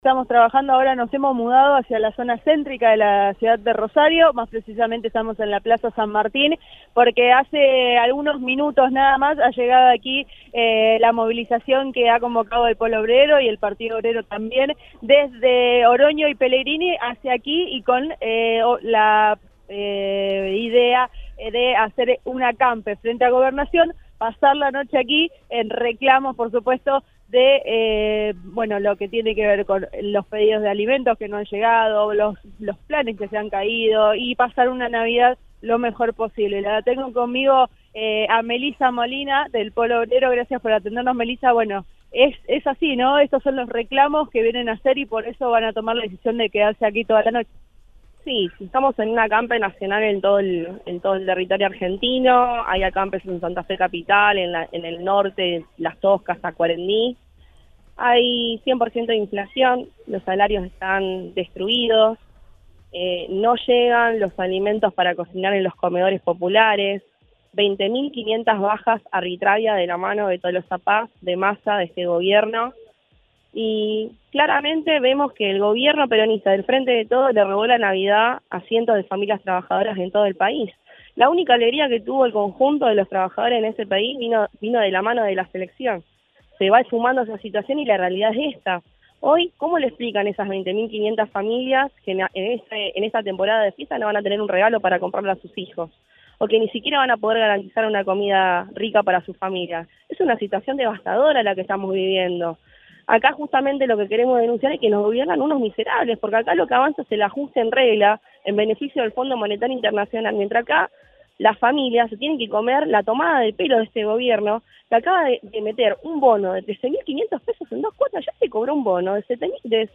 “Hay 100 de inflación, salarios deprimidos, no llegan los alimentos para los comedores populares, es un ajuste de Massa y el gobierno peronista, que le robó la Navidad a miles de trabajadores de todo el país”, dijo una de las manifestantes al móvil de Cadena 3 Rosario.